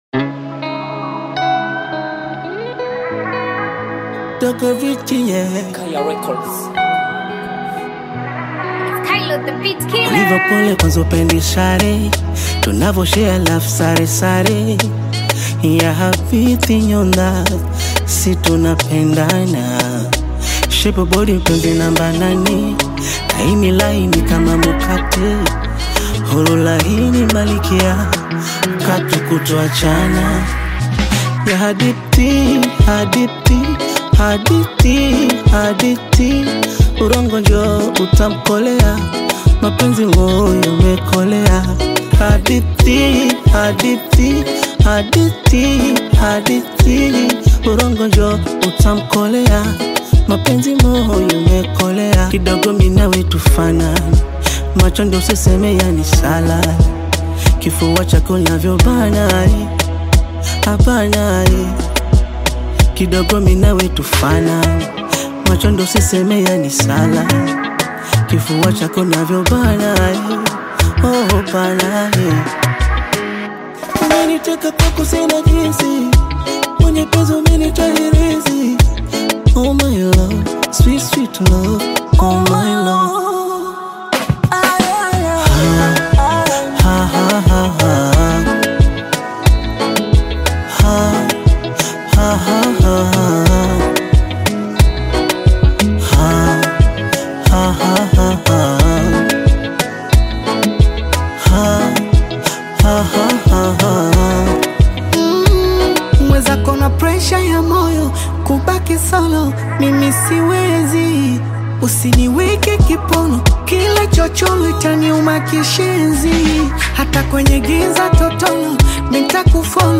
an expression of the most powerful human emotional love.